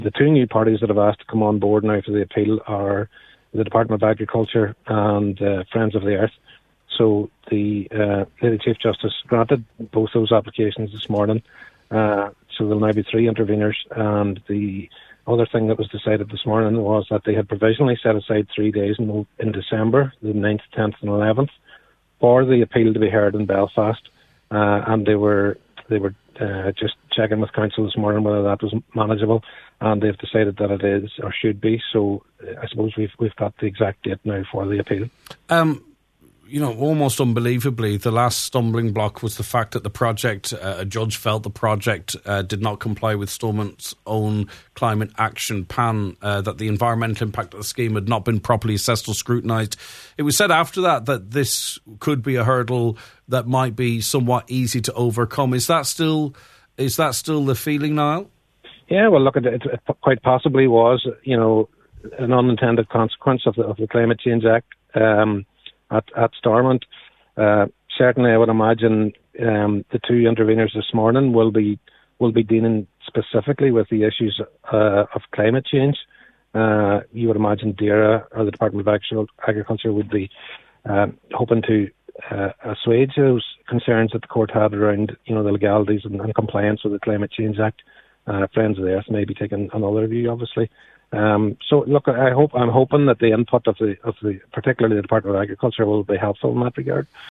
on this morning’s Nine ’til Noon Show